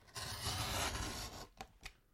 Звуки картона
Звук разрезания картонной коробки канцелярским ножом